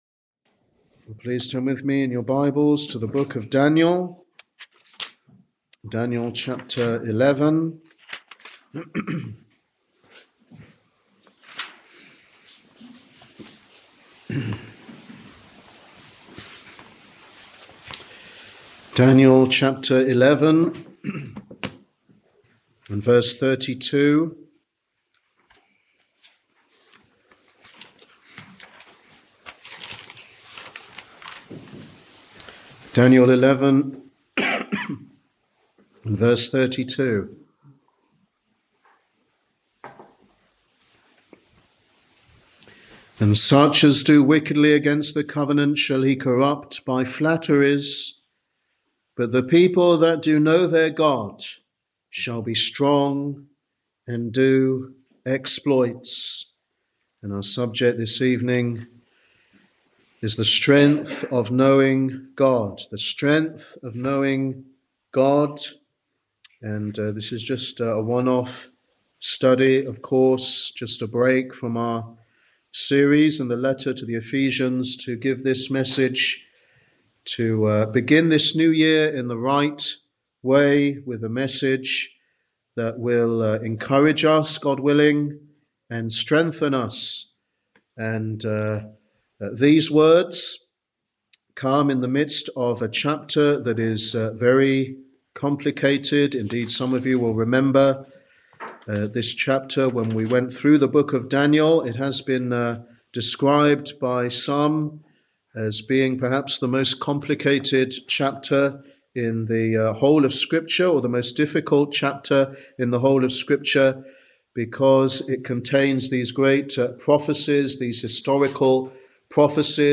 Sunday Teaching Ministry
Sermon